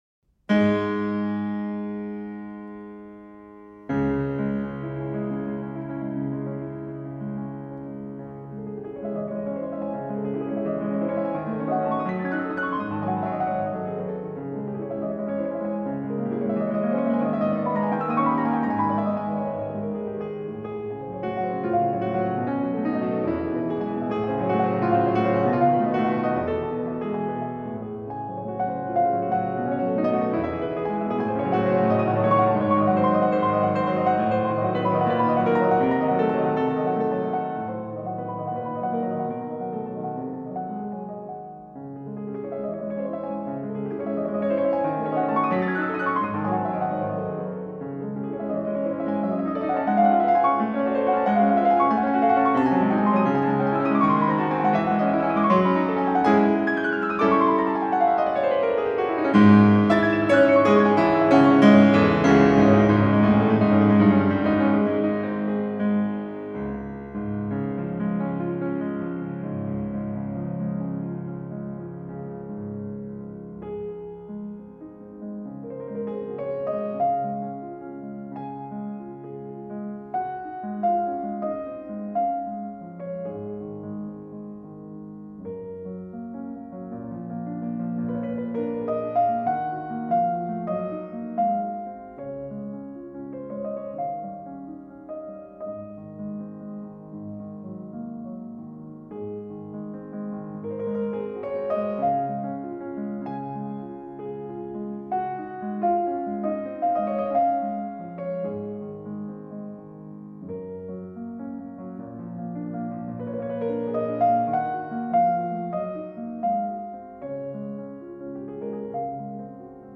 Various-Artists-A-Passion-For-Music-Disc-3-08-Fantasie-Impromptu-In-C-Sharp-Minor-Op.-66-Classical.mp3